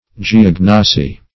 Search Result for " geognosy" : The Collaborative International Dictionary of English v.0.48: Geognosy \Ge*og"no*sy\, n. [Gr. ge`a, gh^, the earth + gnw^sis knowing, knowledge, fr. gignw`skein to know: cf. F. g['e]ognosie.] That part of geology which treats of the materials of the earth's structure, and its general exterior and interior constitution.